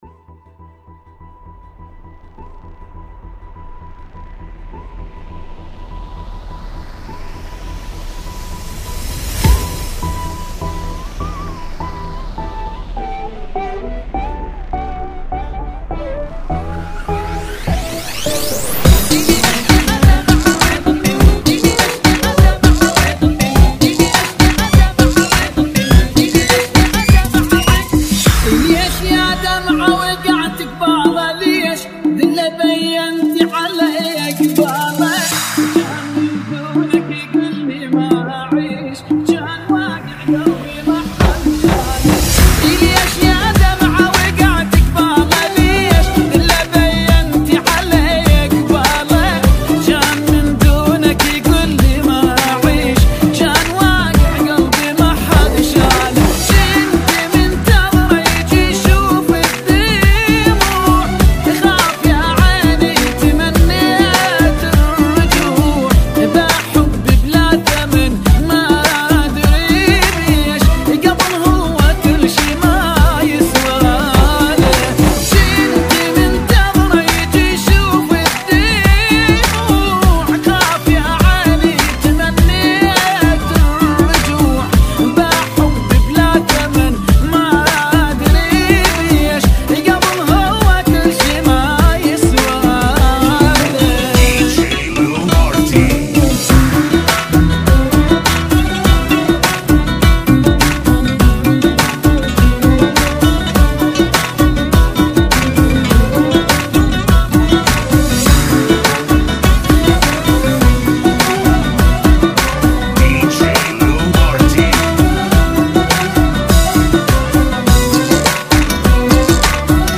Funky Mix 2016 ( Bbm 102